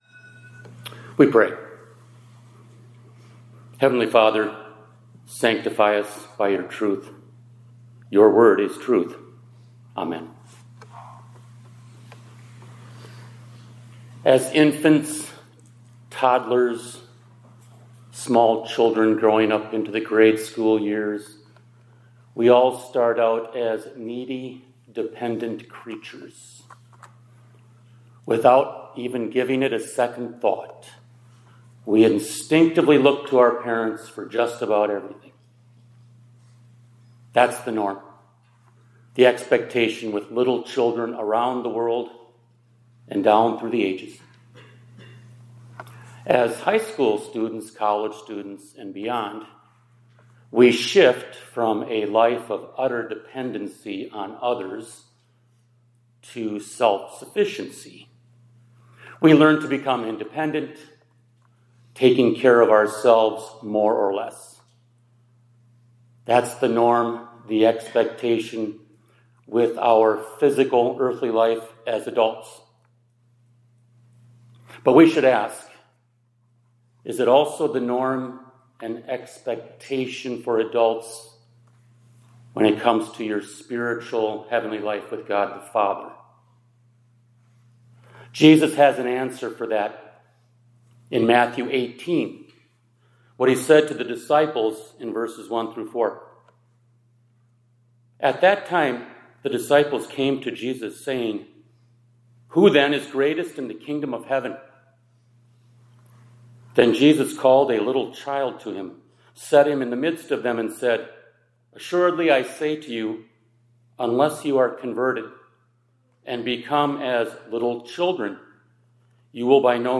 2026-01-26 ILC Chapel — Act Like a Child